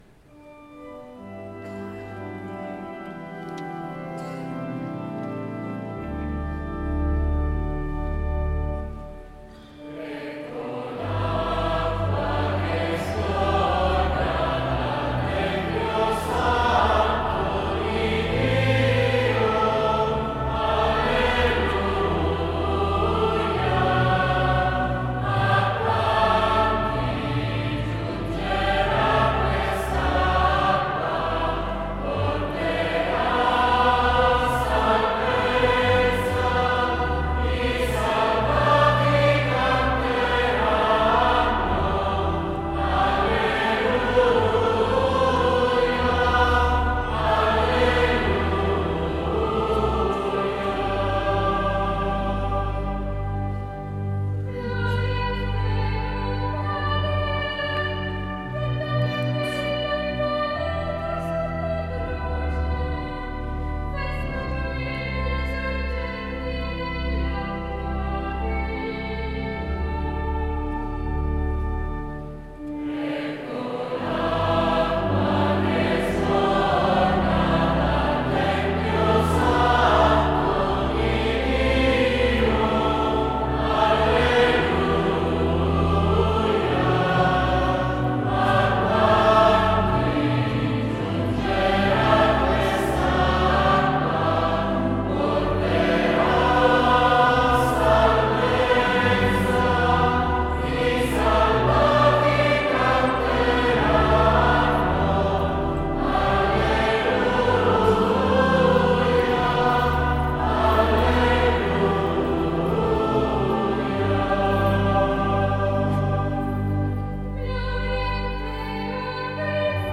Gallery >> Audio >> Audio2017 >> 750 Dedicazione Cattedrale >> 03-Aspersione 750Dedicazione 26Apr2017